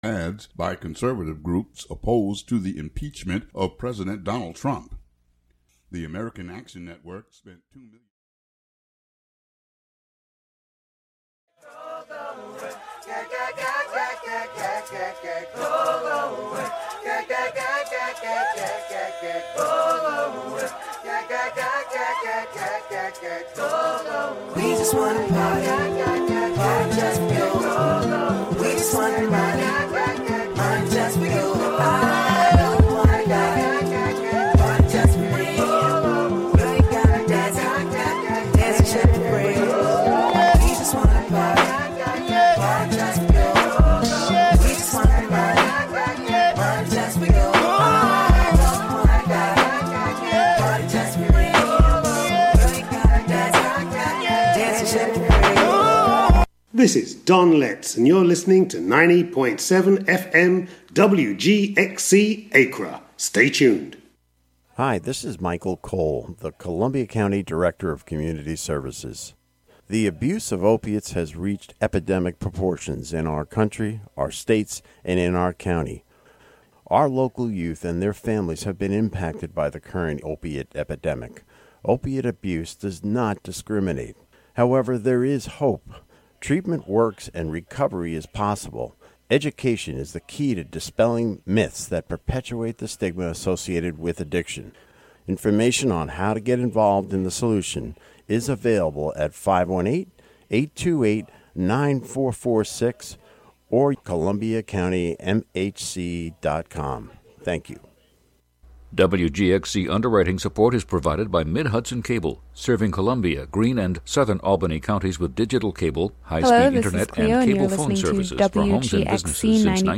On each show, invited guests are asked to discuss a number of items that they would take with them to their Catskill Cabin get-away.